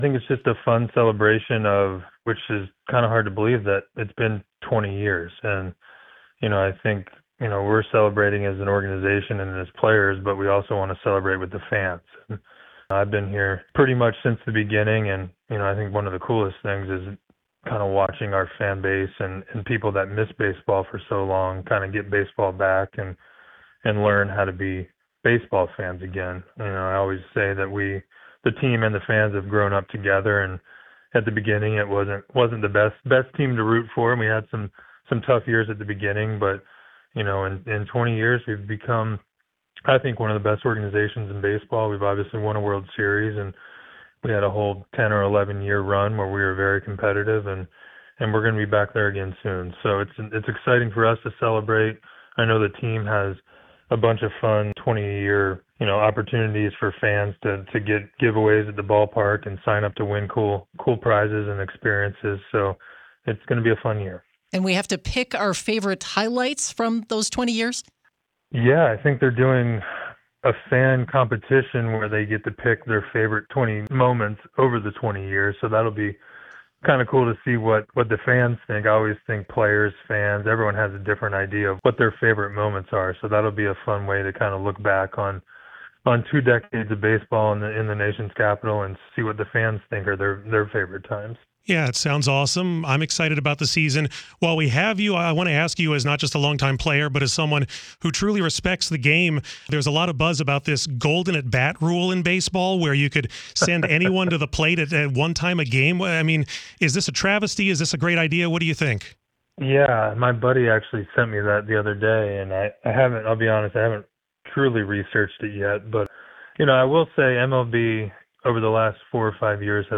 Listen to the full interview below or read the transcript, which has been lightly edited for clarity.